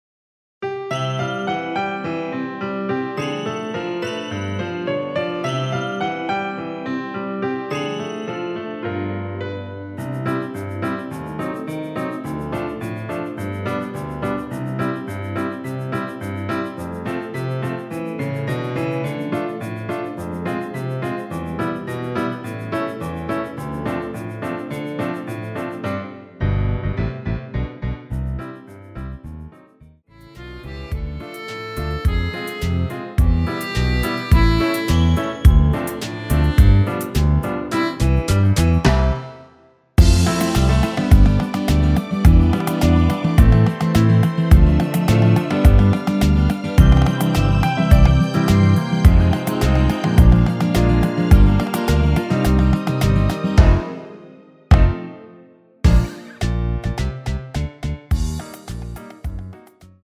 내린 MR
앞부분30초, 뒷부분30초씩 편집해서 올려 드리고 있습니다.
중간에 음이 끈어지고 다시 나오는 이유는